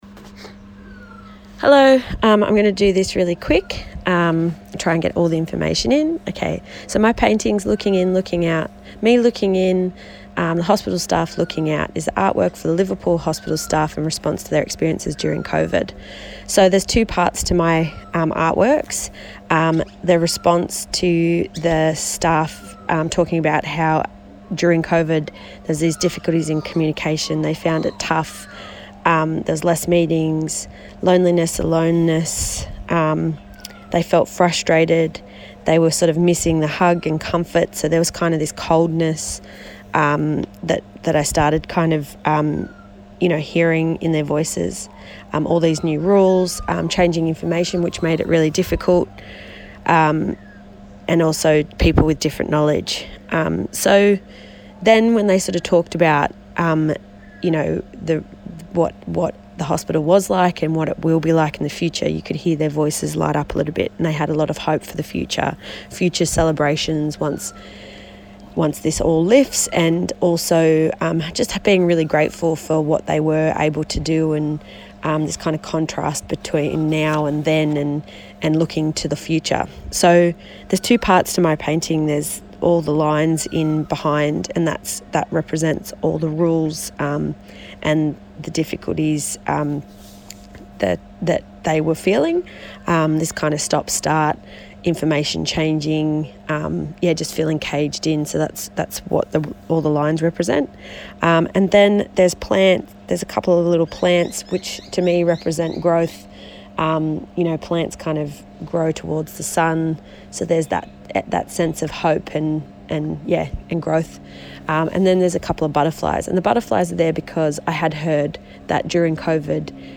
I find it hard to talk about my work, because it is intuitive, so I have recorded my ‘making effect’ of the work you see here.